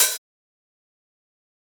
soft-hitfinish2.ogg